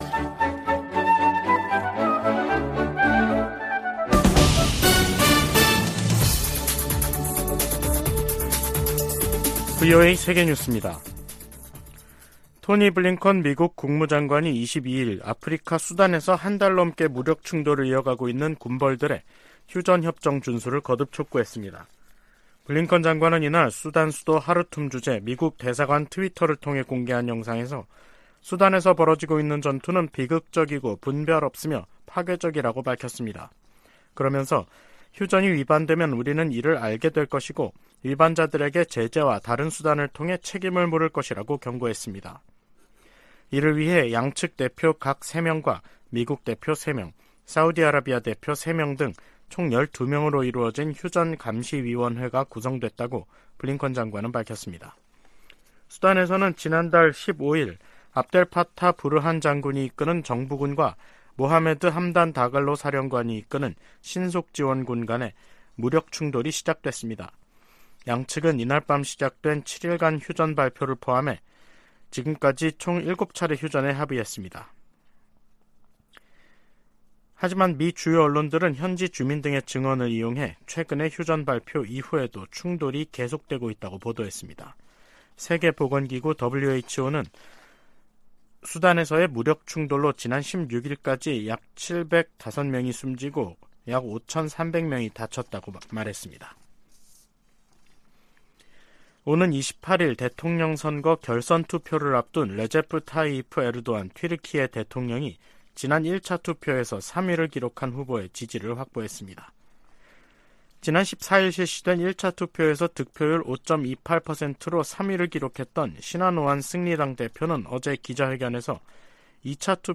VOA 한국어 간판 뉴스 프로그램 '뉴스 투데이', 2023년 5월 23일 3부 방송입니다. 조 바이든 미국 대통령은 일본 히로시마 G7 정상회의로 미한일 3자 협력이 새 차원으로 격상됐다고 말했습니다. G7을 계기로 미한일 공조가 강화되면서 북한, 중국, 러시아의 외교, 안보, 경제의 밀착이 구체화 될 것이라고 전문가들이 내다보고 있습니다. 한국과 유럽연합(EU) 정상이 북한의 도발 행위를 강력히 규탄하며 비핵화 대화에 복귀할 것을 촉구했습니다.